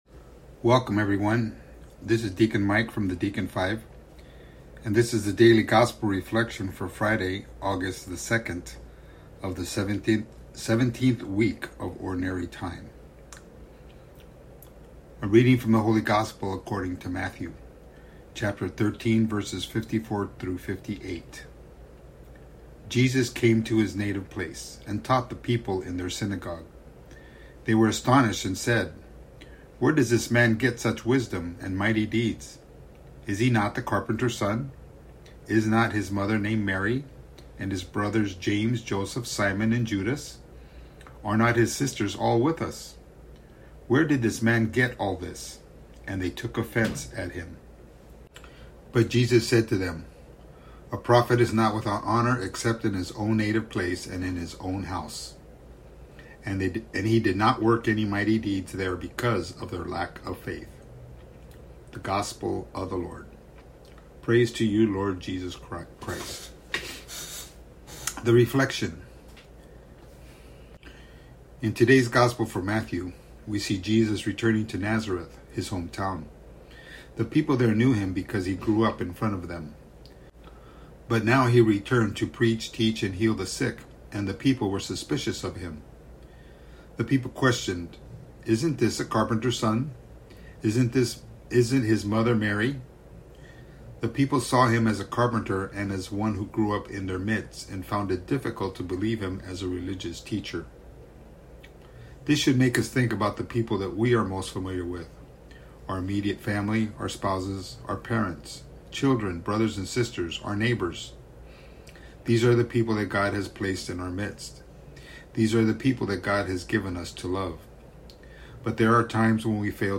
A Daily Gospel Reflection